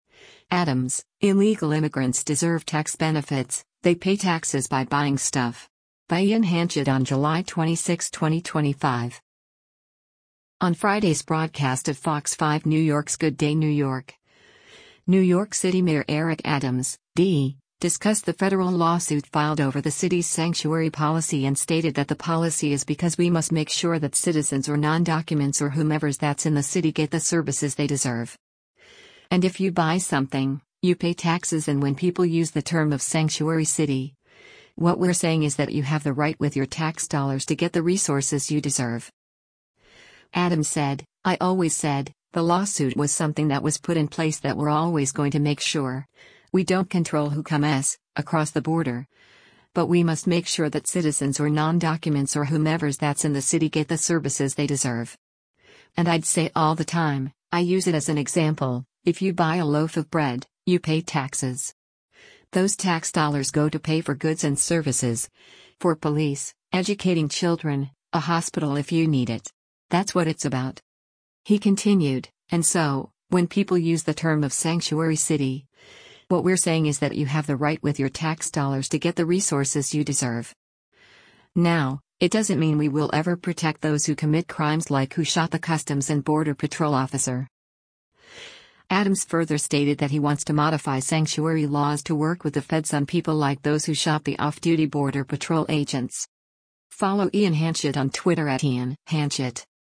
On Friday’s broadcast of Fox 5 New York’s “Good Day New York,” New York City Mayor Eric Adams (D) discussed the federal lawsuit filed over the city’s sanctuary policy and stated that the policy is because “we must make sure that citizens or non-documents or whomevers that’s in the city get the services they deserve.”